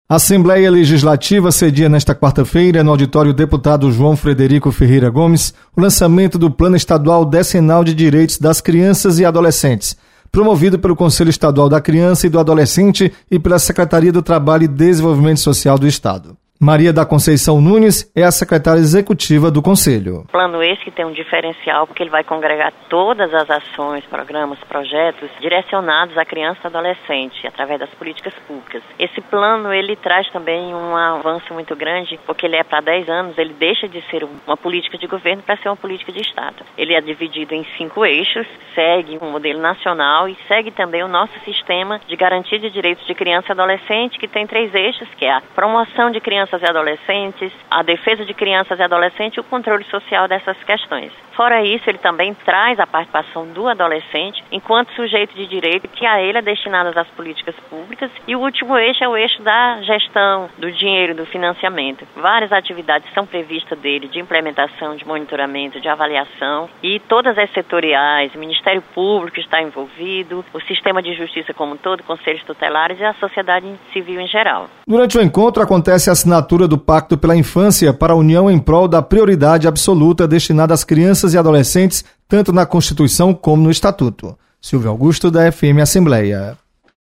Encontro na Assembleia debate direitos das crianças e adolescentes. Repórter